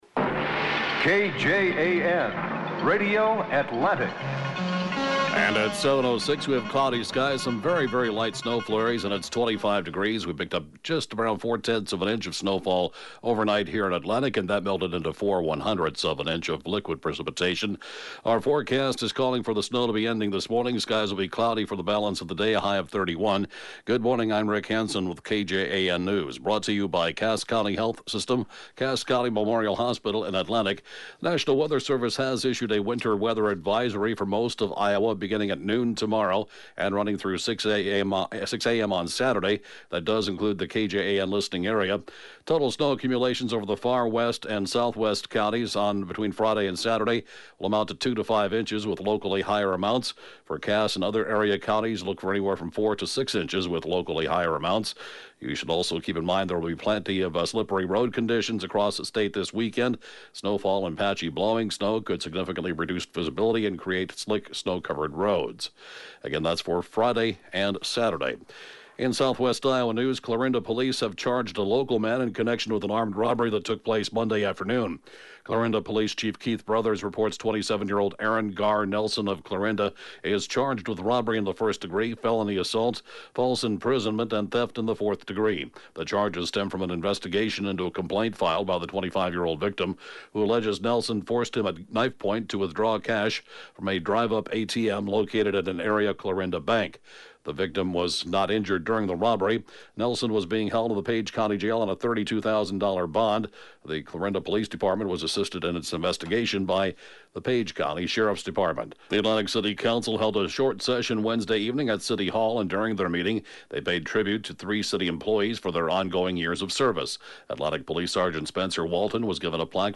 (Podcast) KJAN Morning News & Funeral report, 1/17/2019